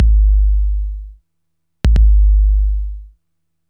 HBA1 11 bass01.wav